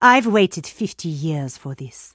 Lady Geist voice line - I've waited 50 years for this.